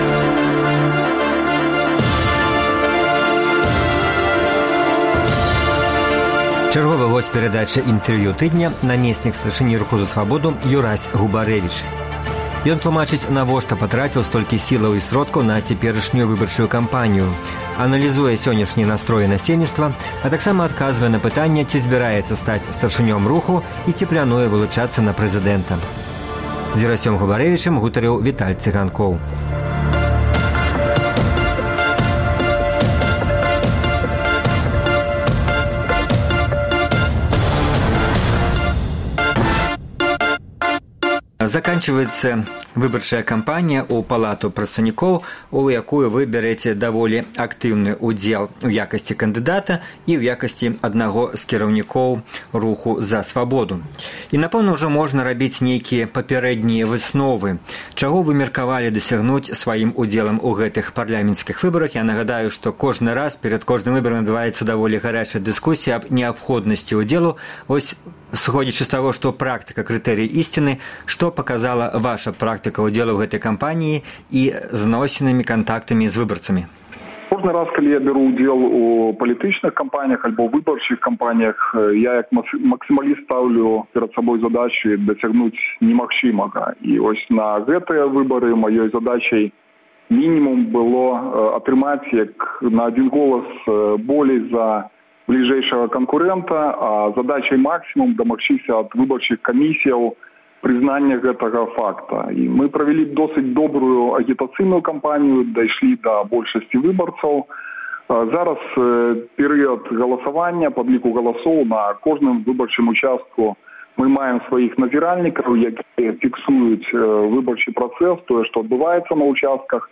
Чарговы герой перадачы «Інтэрвію тыдня»